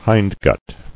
[hind·gut]